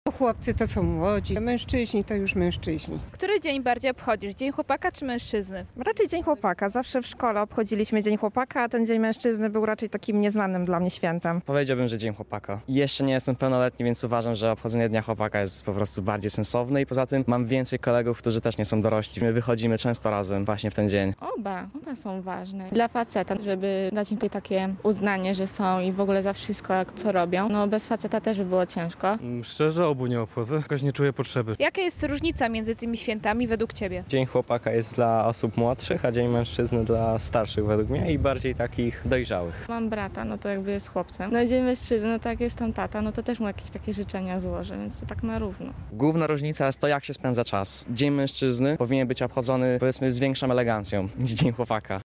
Dzień Chłopaka. Co o tym święcie myślą mieszkańcy Rzeszowa? (sonda)
Nasi reporterzy zapytali o to mieszkańców Rzeszowa.
sonda.mp3